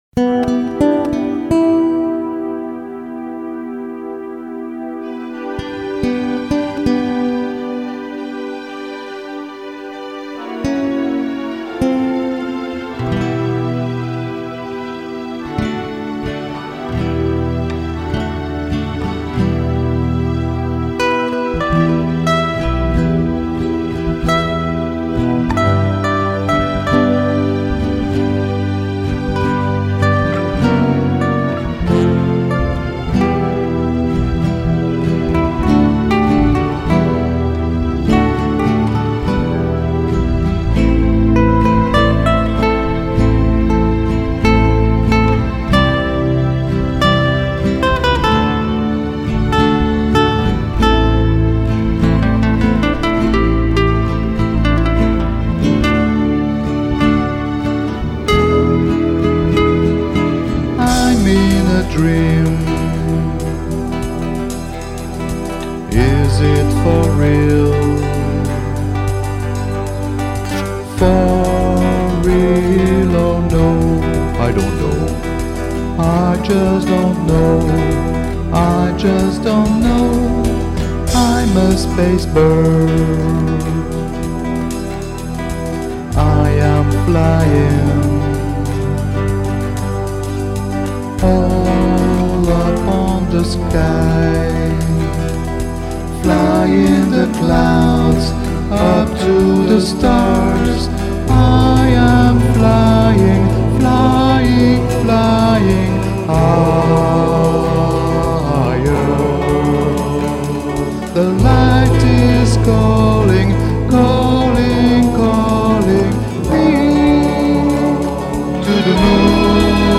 Paroles, musique, voix, guitare, accompagnements
Violons